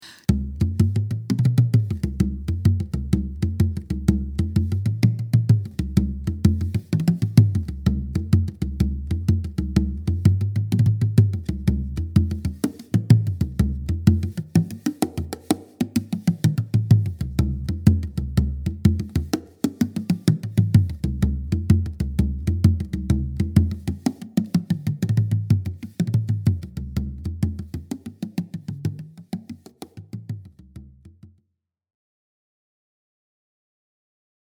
Bodhran-Basics.mp3